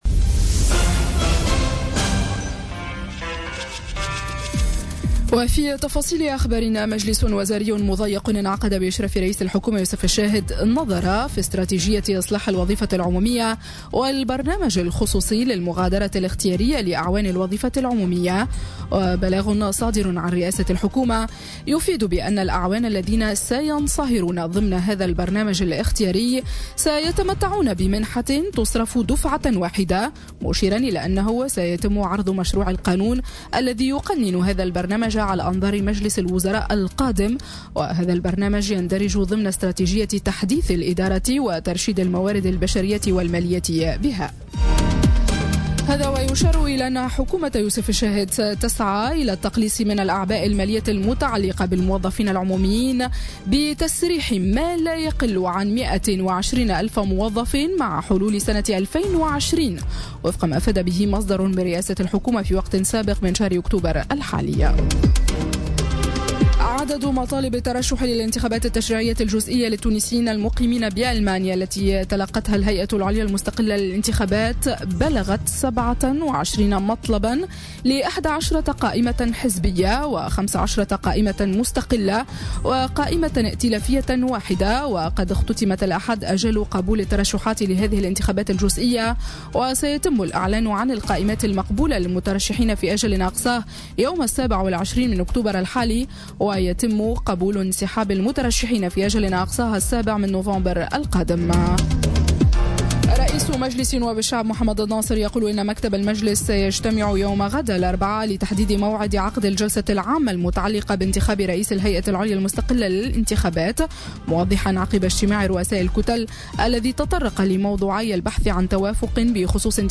نشرة أخبار السابعة صباحا ليوم الثلاثاء 24 أكتوبر 2017